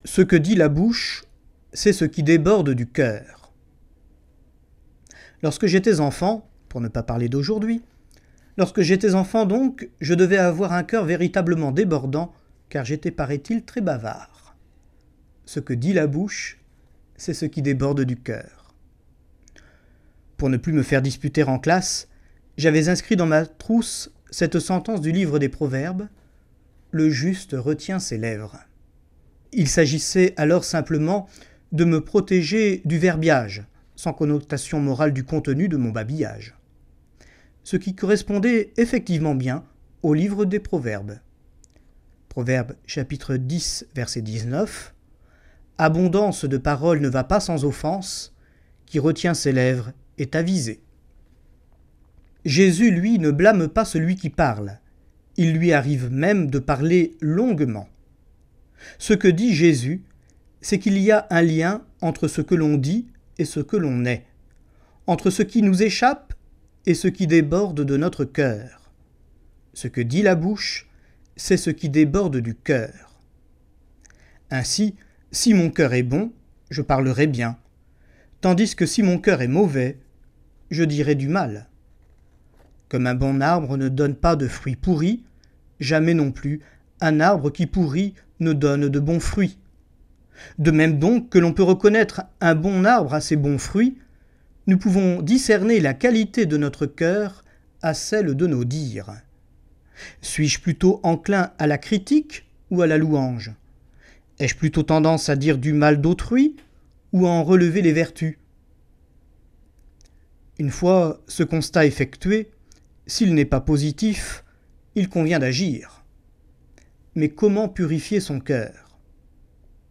Commentaire d’Evangile